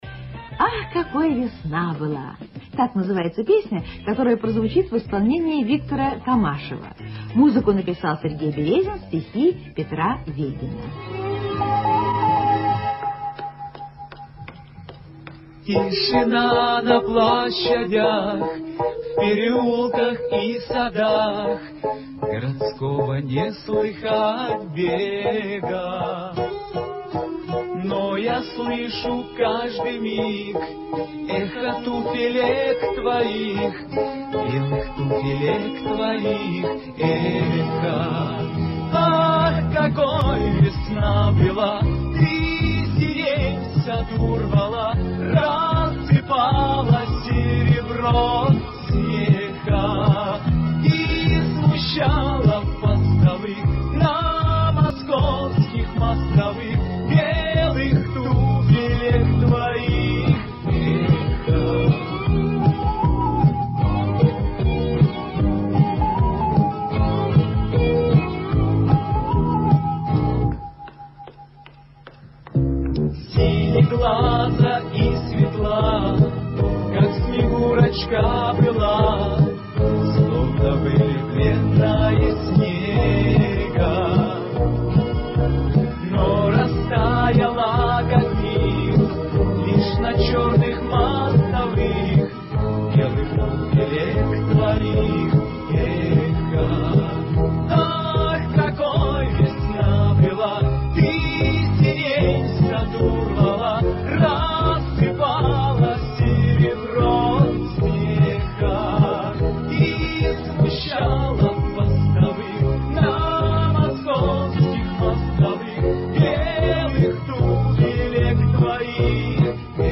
Есть ли эта песня в лучшем качестве???